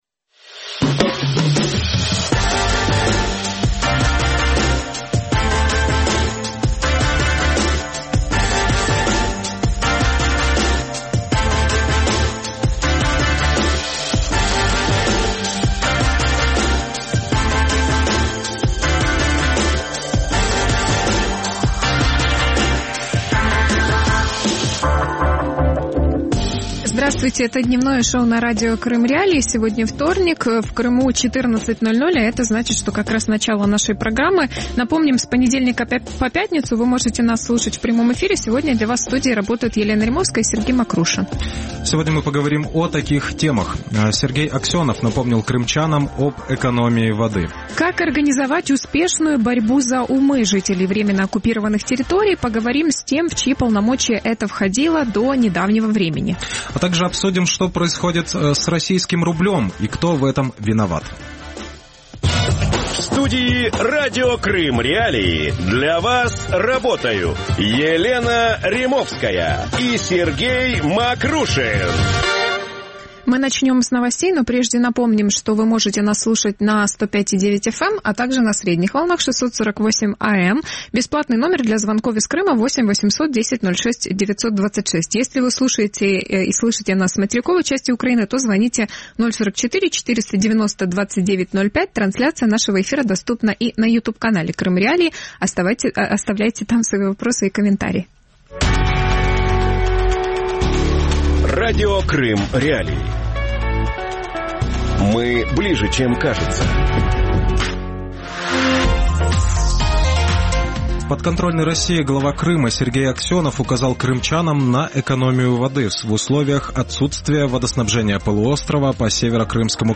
Вещание на Крым и «борьба за умы» крымчан | Дневное ток-шоу